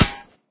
metalgrass3.ogg